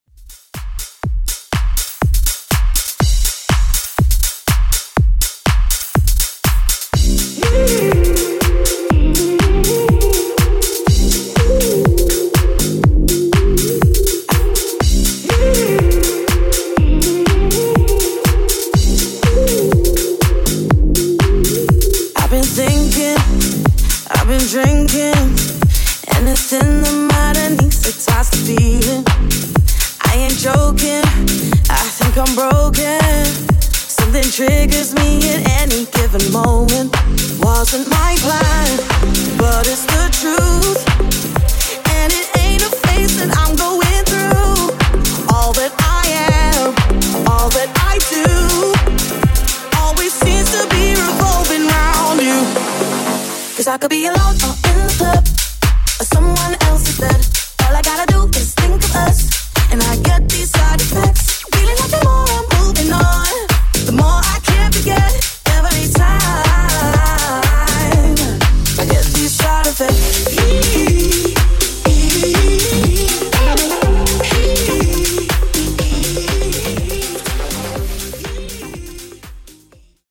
Dance Club Mix)Date Added